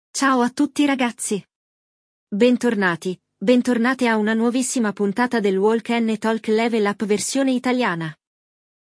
In questo episodio di Walk ‘n’ Talk Level Up, hai ascoltato il dialogo tra due amiche che parlano di una nuova canzone e decidono di andare a un concerto.
É um podcast interativo para aprender italiano com diálogos do dia a dia e explicações didáticas.
Duas amigas conversam sobre uma nova música e combinam de ir a um show em Milão.